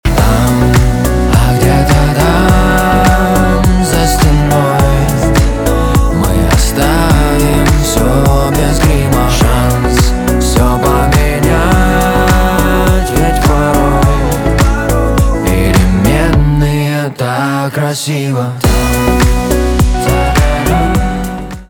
Гитара , поп
чувственные